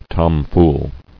[tom·fool]